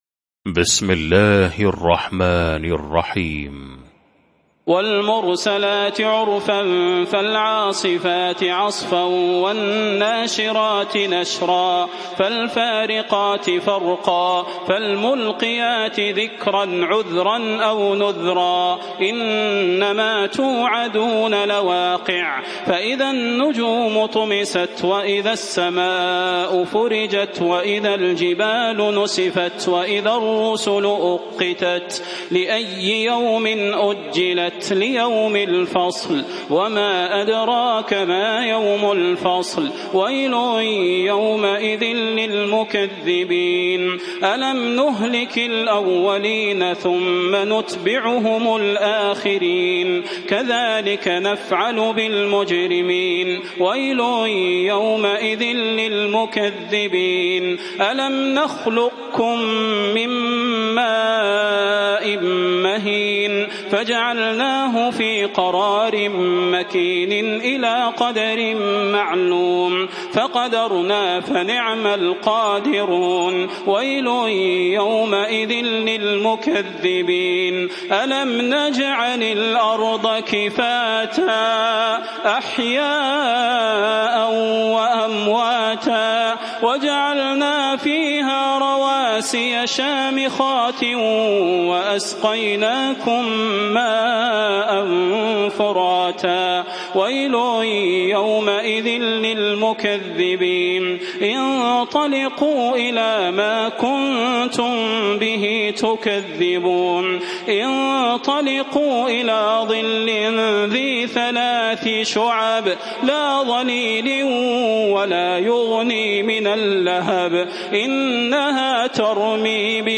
المكان: المسجد النبوي الشيخ: فضيلة الشيخ د. صلاح بن محمد البدير فضيلة الشيخ د. صلاح بن محمد البدير المرسلات